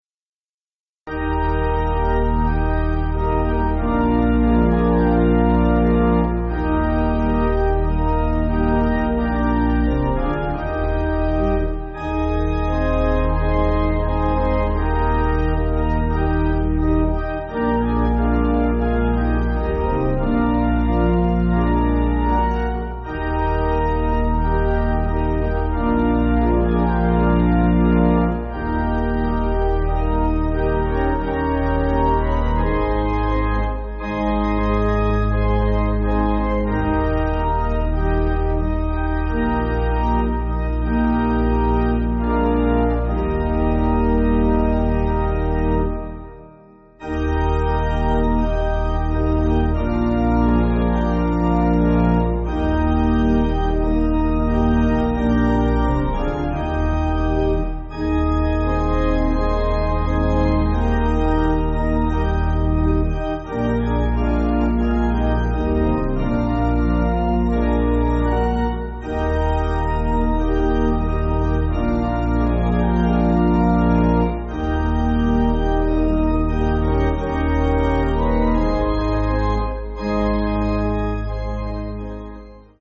Organ
(CM)   3/Eb